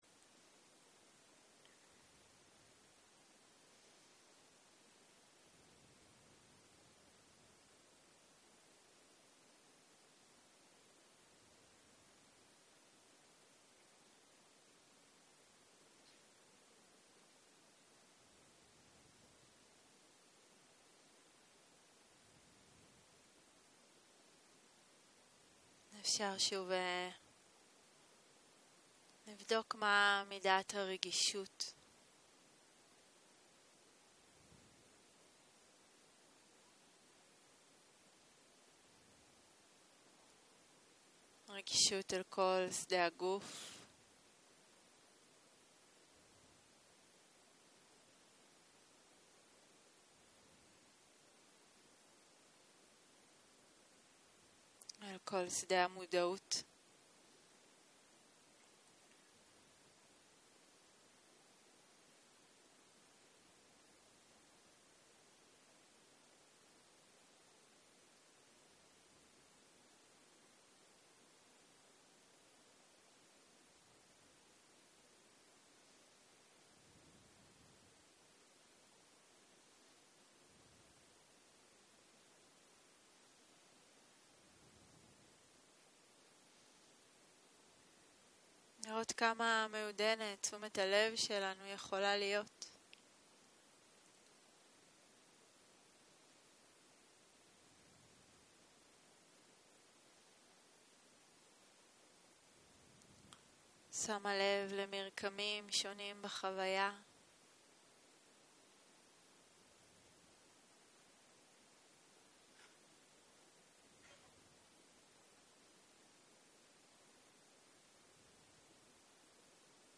יום 5 - ערב - מדיטציה מונחית - להרפות כיווץ - הקלטה 13
Dharma type: Guided meditation שפת ההקלטה